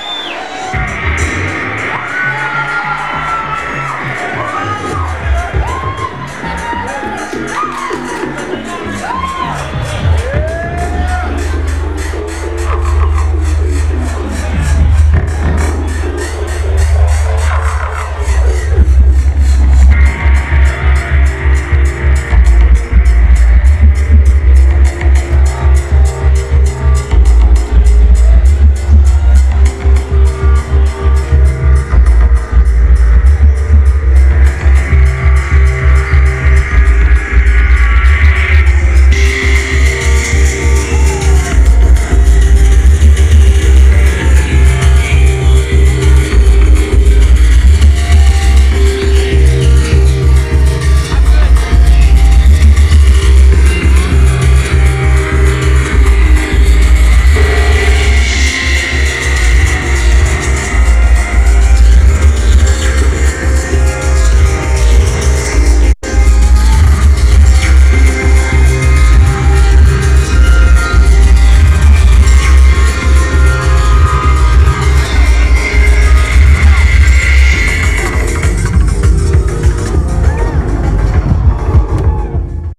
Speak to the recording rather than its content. venue Old Brickhouse Grill